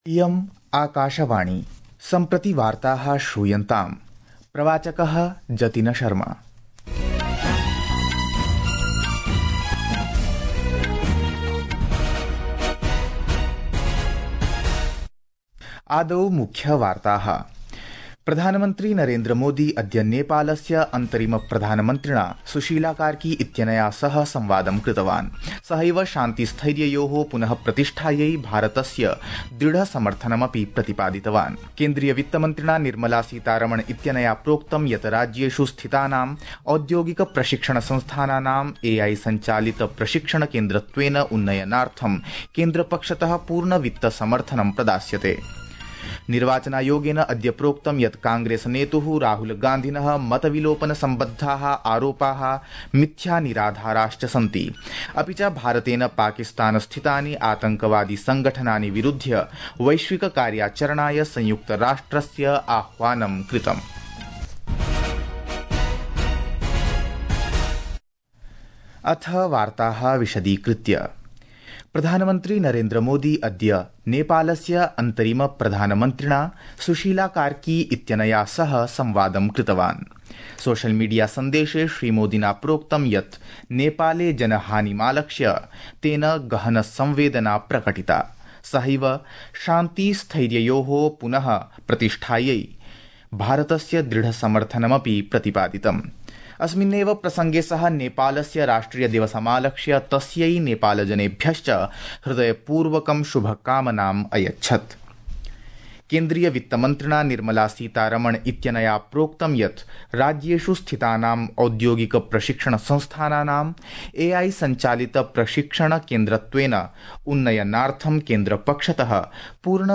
Sanskrit-News-1.mp3